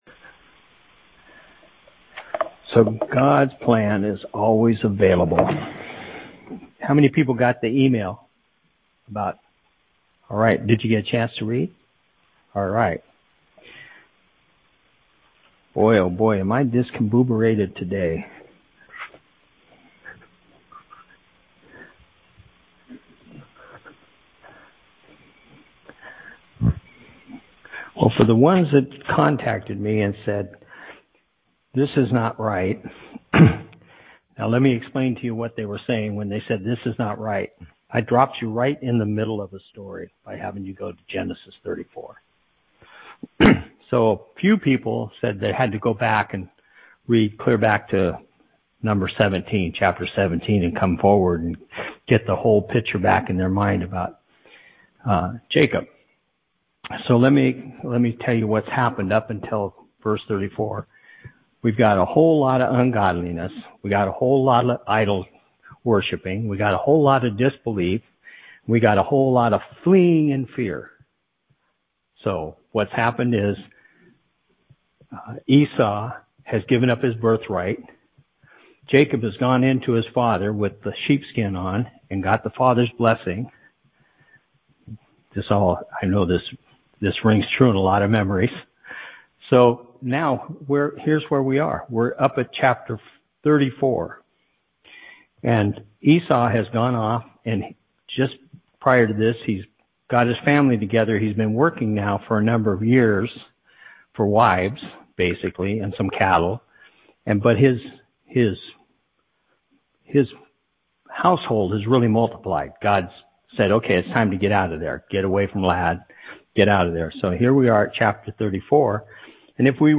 Audio of the Message from our Service